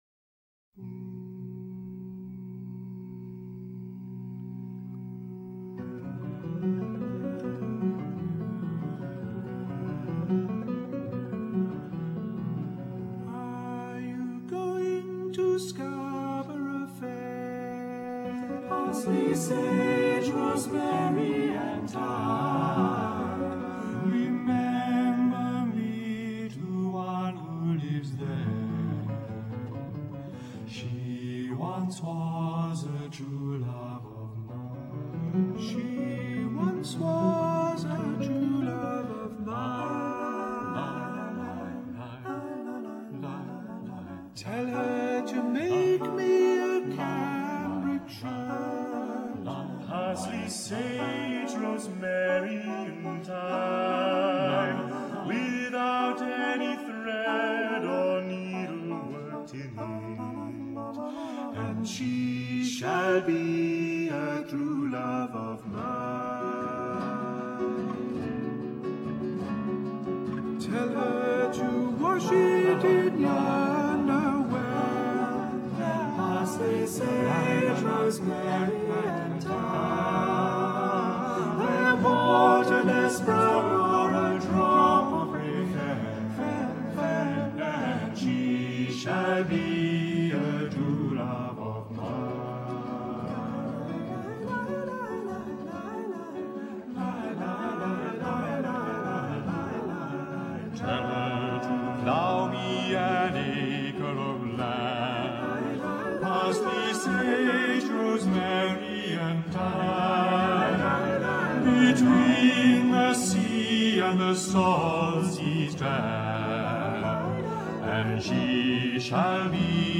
(English folk song-- Anon.)
Scarborough Fair - traditional English